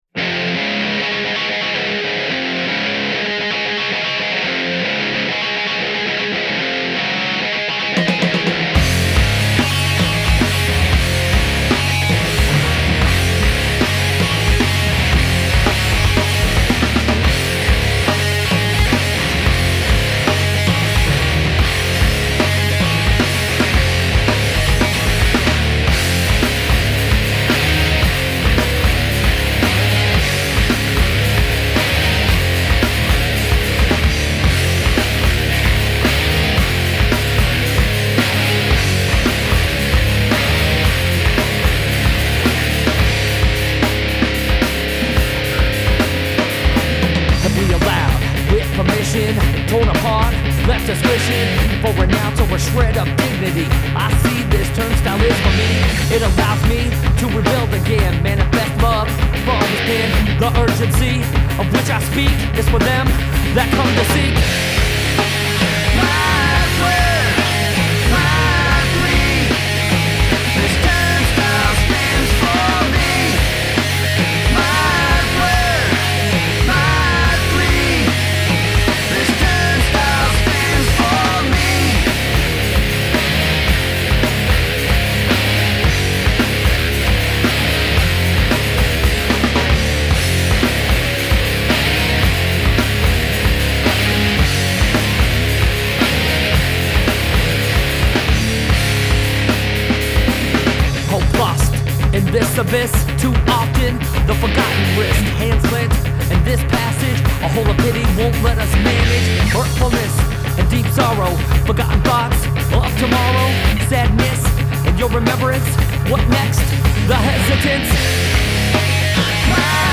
Rock, Metal